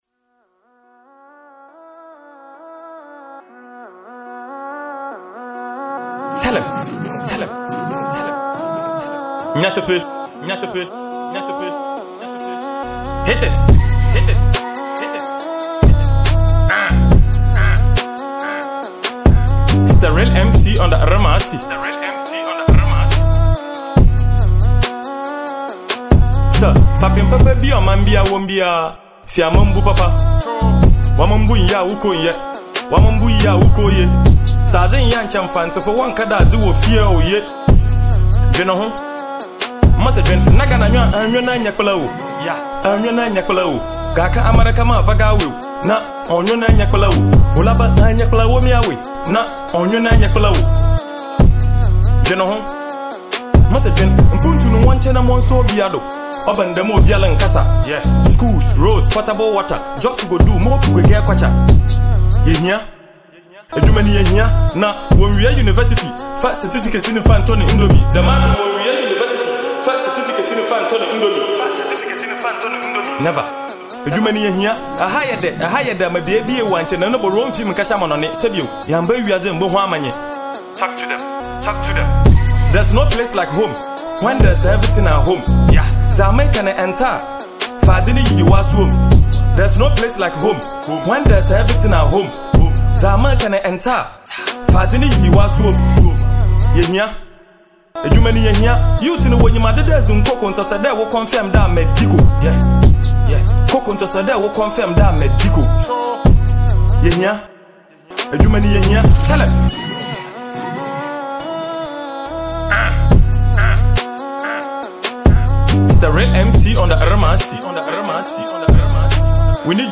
banging Hiphop song